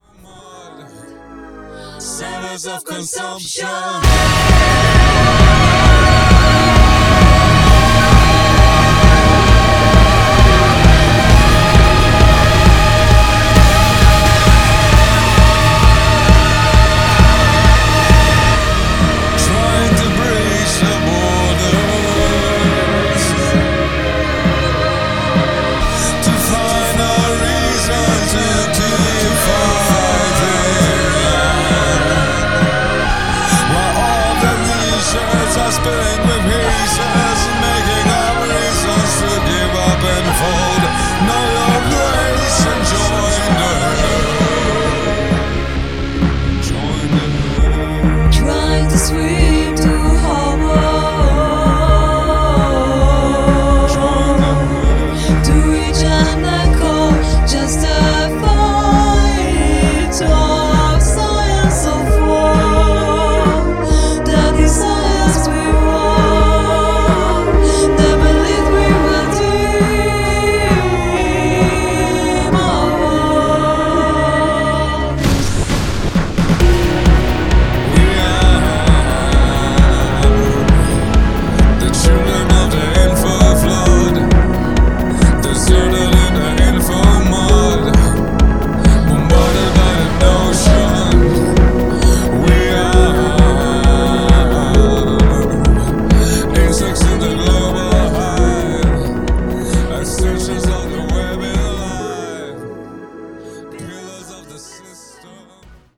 an alternative industrial rock outfit
the British trip hop sound the likes of Massive Attack